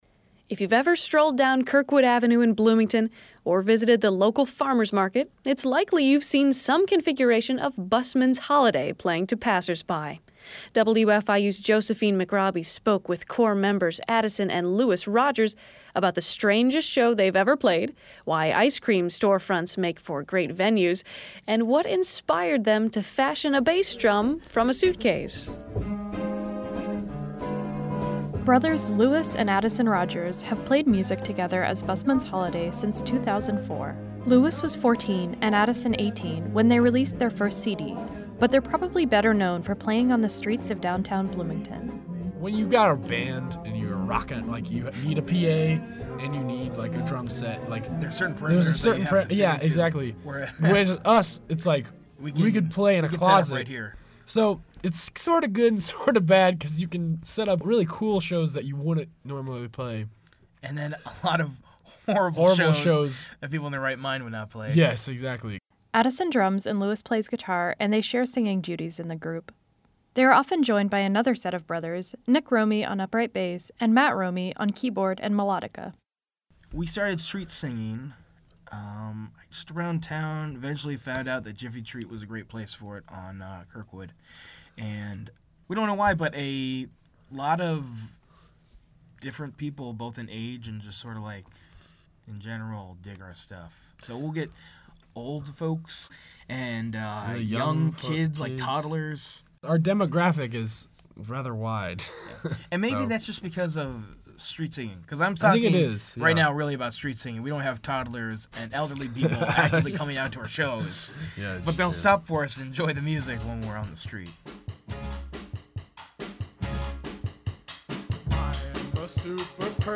The use of instruments not typically used by buskers makes for a unique attraction.